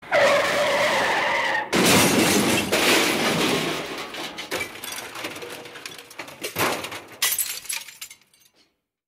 Car Skid and Crash